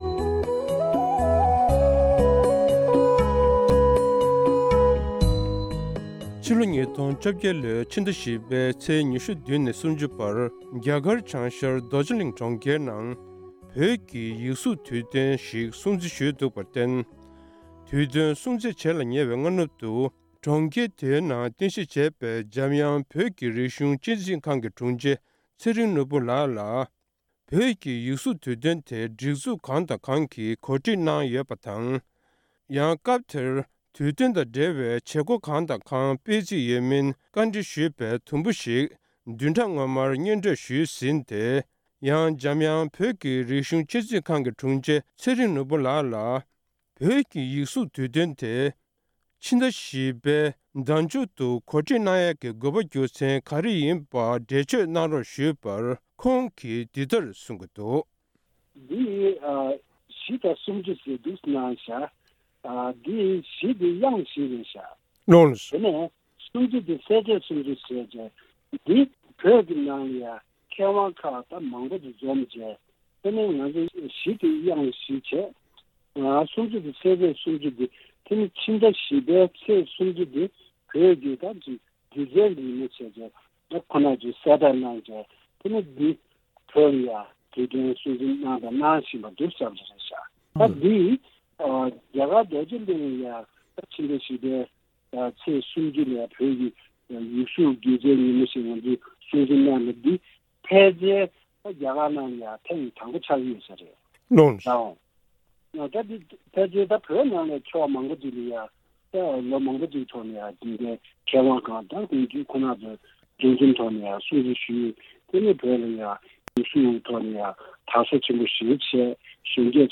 བོད་ཀྱི་ཡིག་གཟུགས་རྒྱུ་སྩལ་གྱི་དུས་སྟོན་༢། སྒྲ་ལྡན་གསར་འགྱུར།